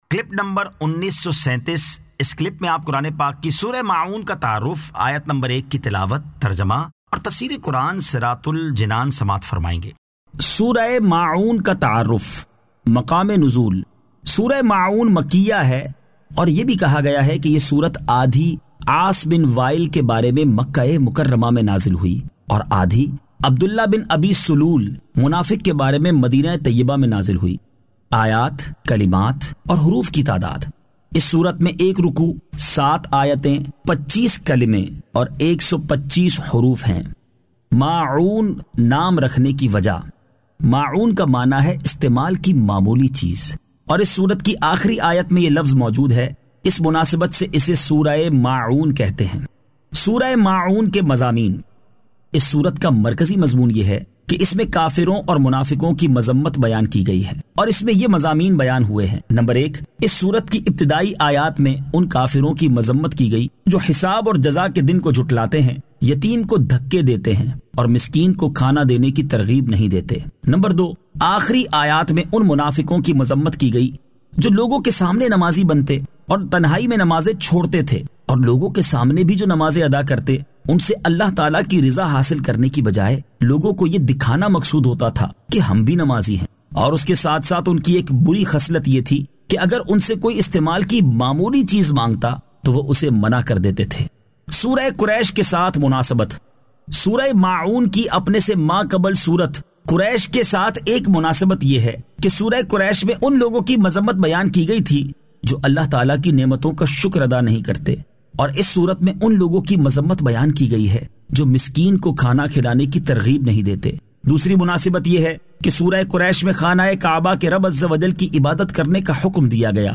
Surah Al-Ma'un 03 To 04 Tilawat , Tarjama , Tafseer